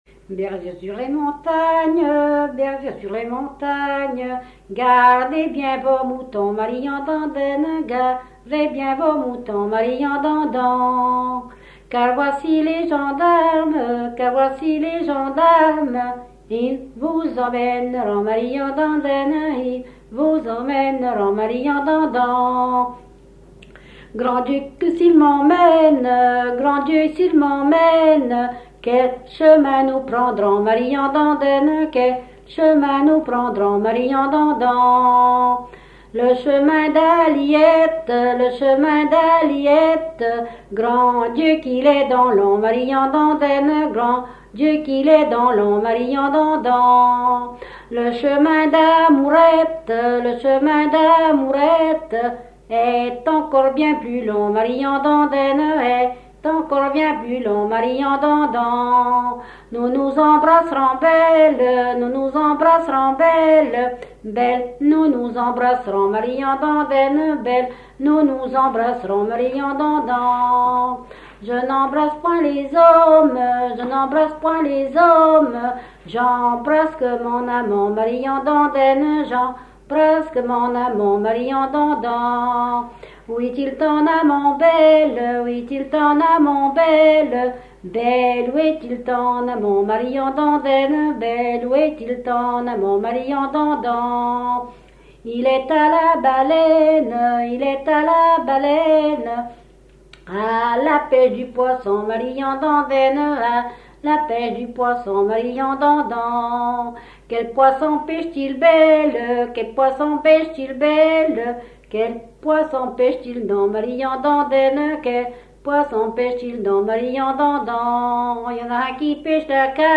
Localisation Barbâtre (Plus d'informations sur Wikipedia)
Fonction d'après l'analyste danse : ronde ;
Genre laisse
Catégorie Pièce musicale inédite